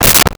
Car Door Closed 04
Car Door Closed 04.wav